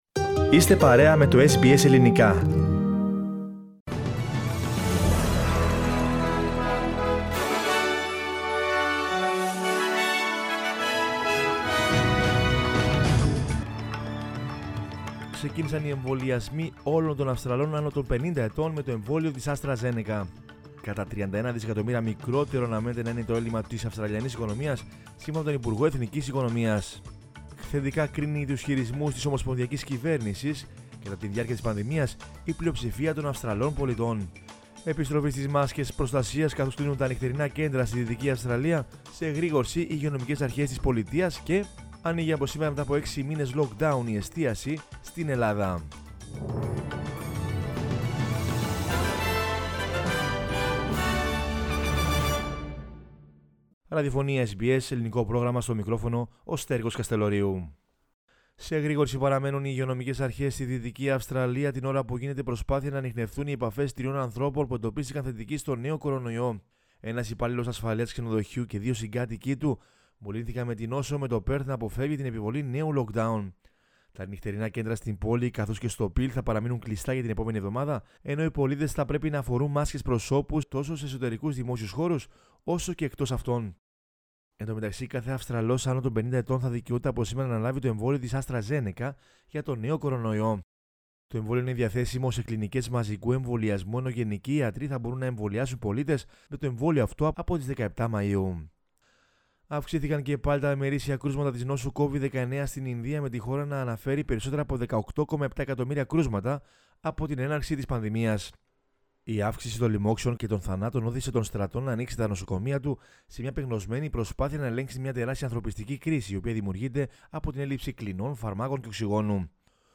News in Greek from Australia, Greece, Cyprus and the world is the news bulletin of Monday 3 May 2021.